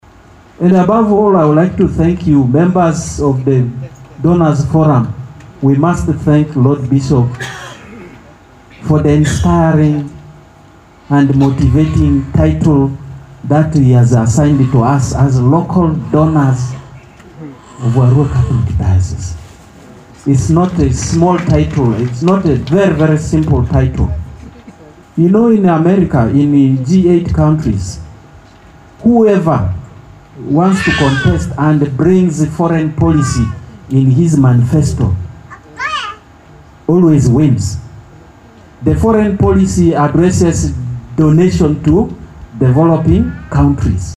The chairman of the local council in Arua district